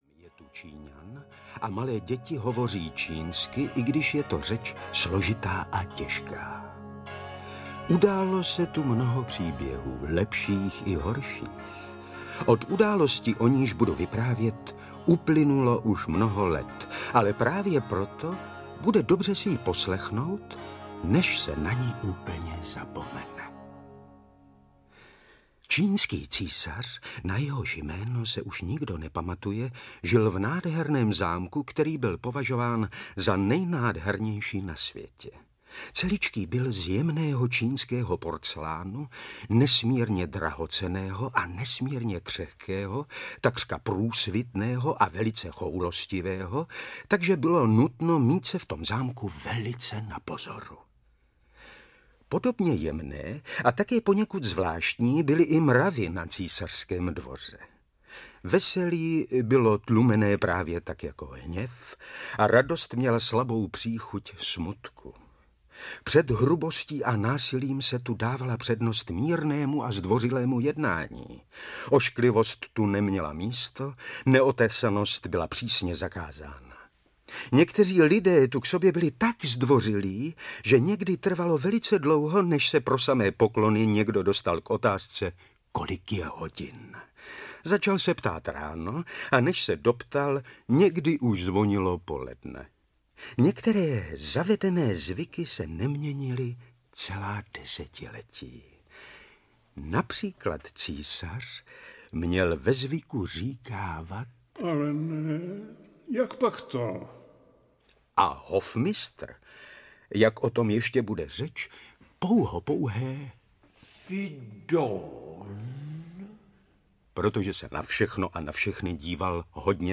Ošklivé káčátko a další dvě pohádky audiokniha
Ukázka z knihy